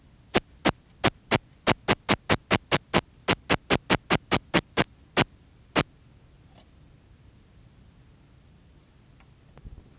10 s of calling song and waveform. Ventura County, California; 21.3°C. S18-26, R19-15.